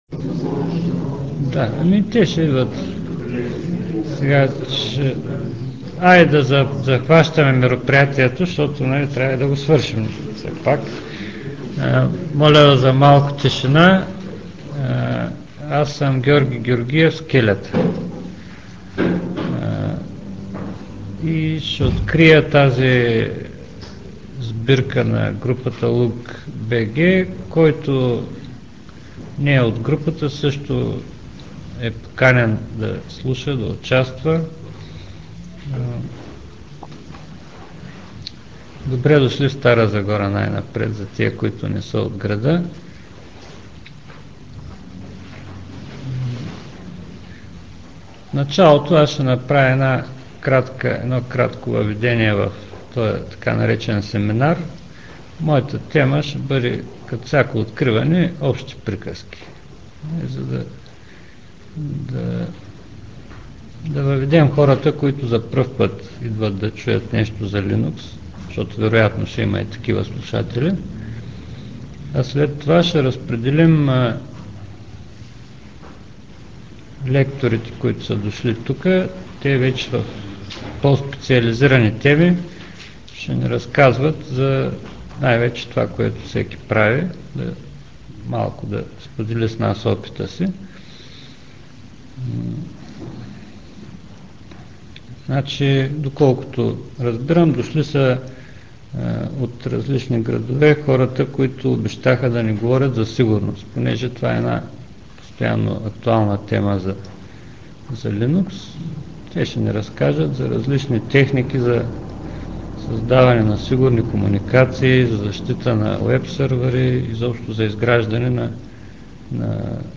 Трети Линукс семинар на LUG-BG - Стара Загора май 2000г.
За съжаление, от семинар 2000г. разполагаме само със звукови записи на лекциите.